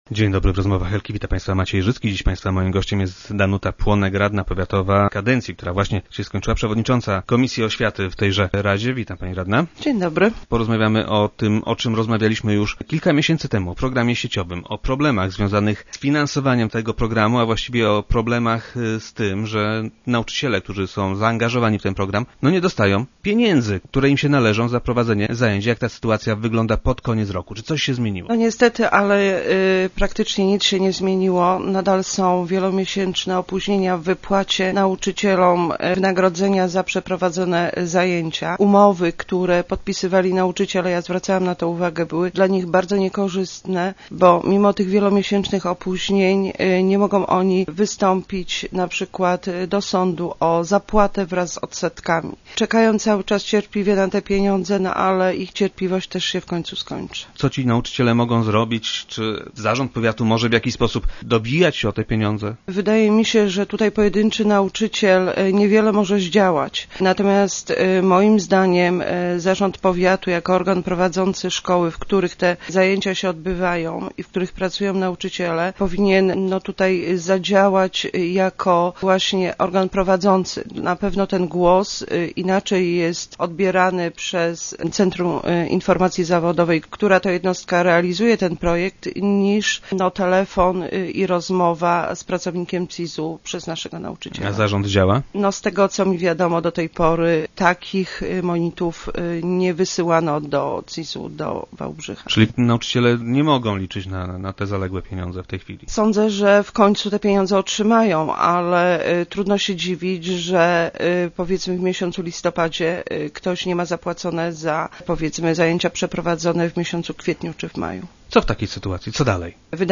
Gościem poniedziałkowych Rozmów Elki była Danuta Płonek, nauczycielka oraz przewodnicząca komisji oświaty w radzie powiatu głogowskiego.